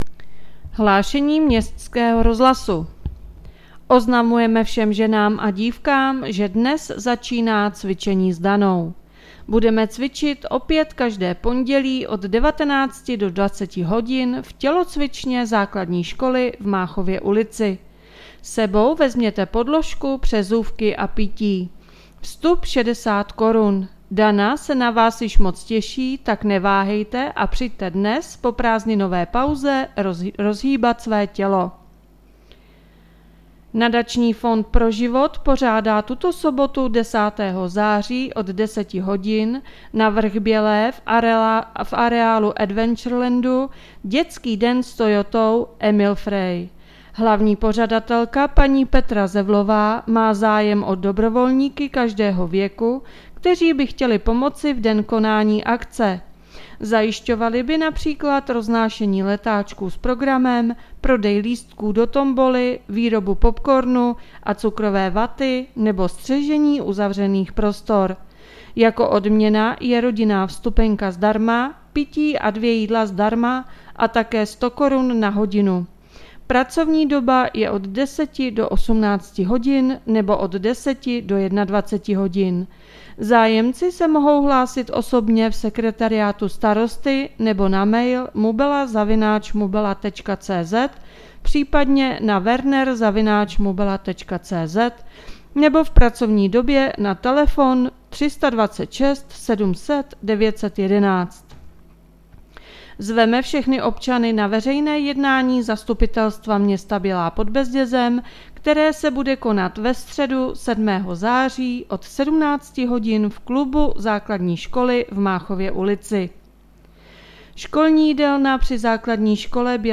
Hlášení městského rozhlasu 5.9.2022